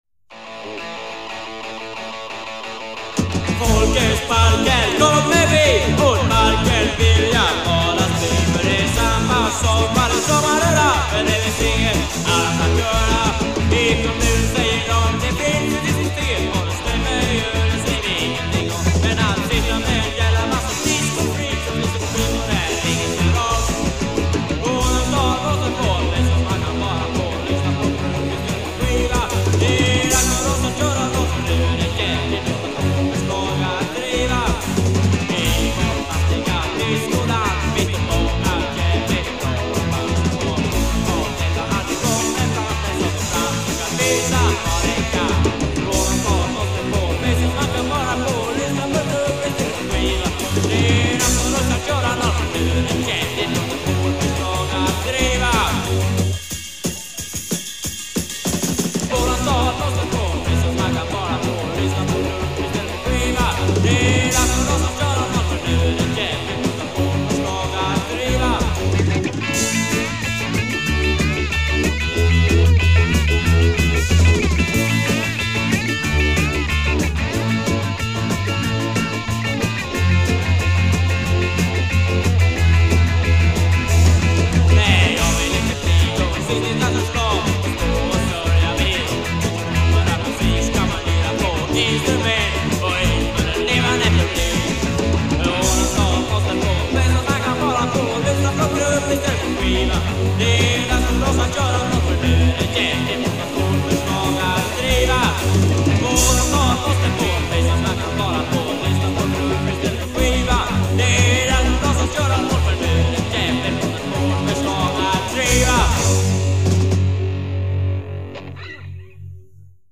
Guitar
Voice
Bass
Drums
Keyboards
Recorded in Blästadgården and at some basements in Vidingsjö